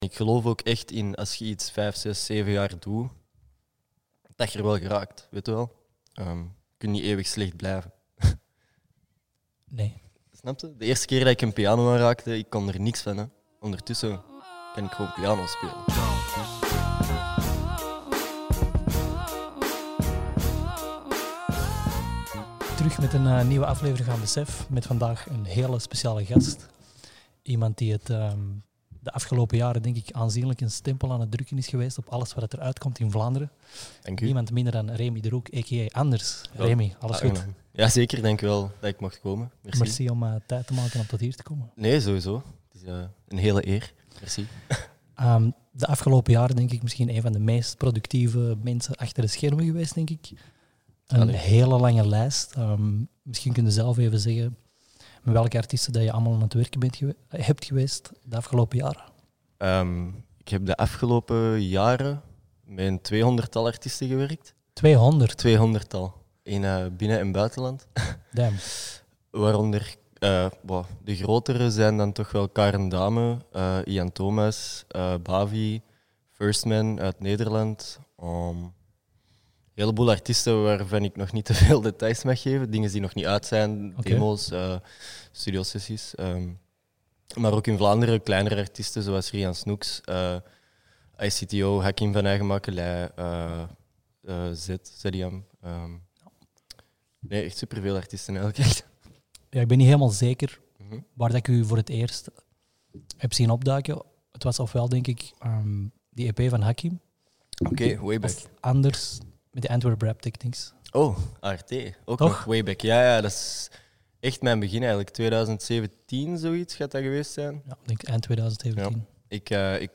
In Besef de podcast hoor je elke aflevering het verhaal van iemand uit de (Belgische) muziekindustrie. De talkshow hoopt het gebrek aan informatie over heel wat talenten weg te werken, door hen via dit kanaal, een platform aan te bieden voor hun verhalen.
Be a guest on this podcast Language: nl Genres: Music , Music History , Music Interviews Contact email: Get it Feed URL: Get it iTunes ID: Get it Get all podcast data Listen Now...